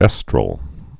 (ĕstrəl)